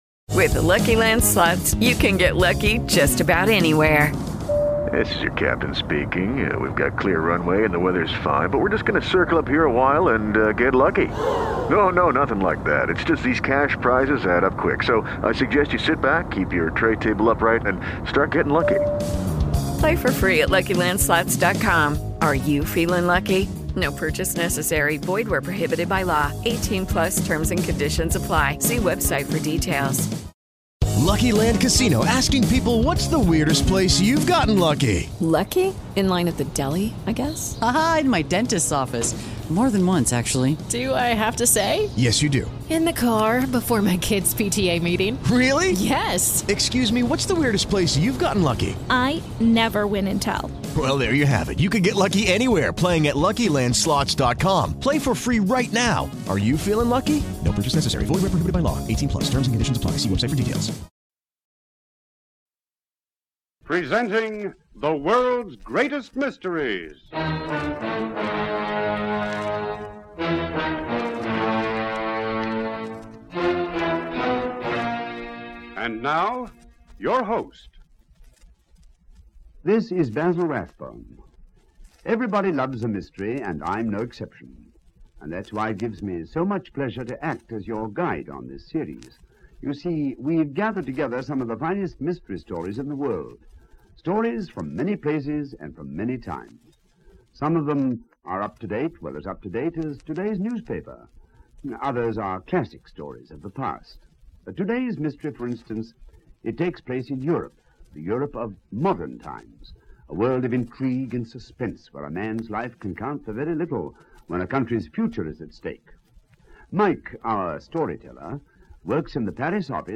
Europe Confidential, a classic from the golden age of radio!